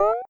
Alert2.wav